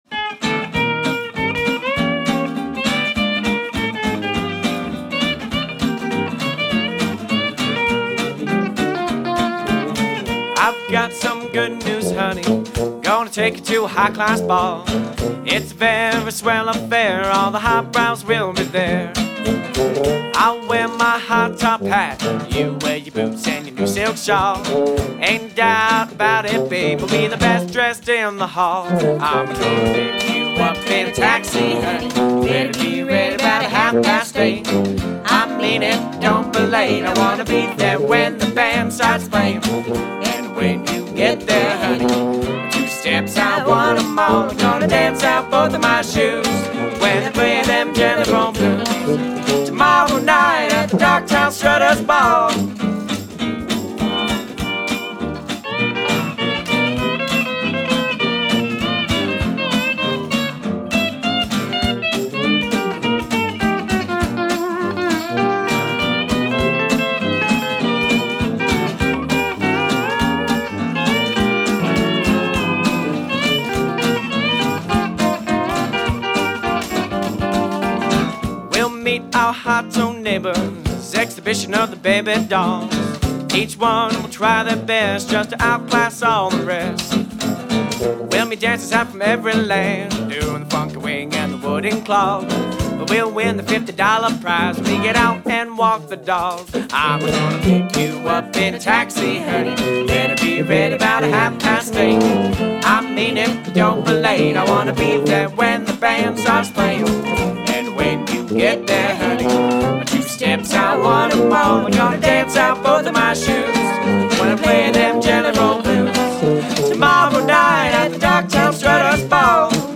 The hot and sweet stylings
banjo playing
one member of the trad-jazz group